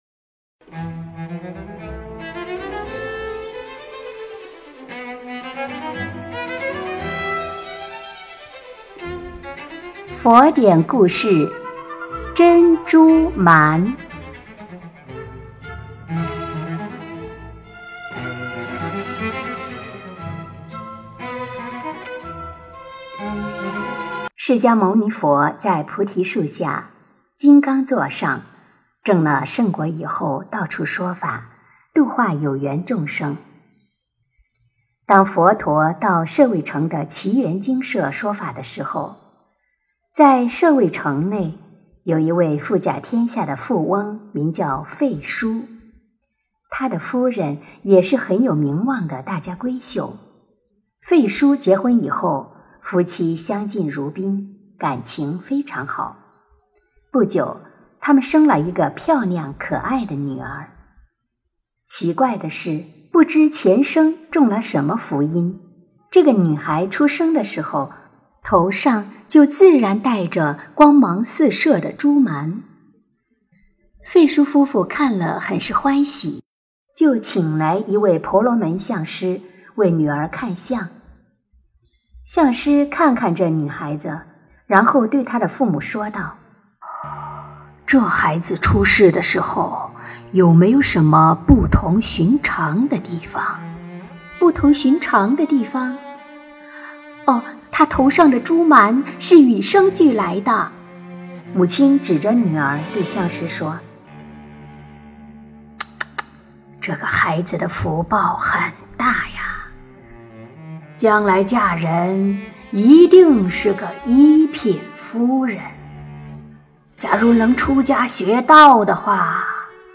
佛音 诵经 佛教音乐 返回列表 上一篇： 09.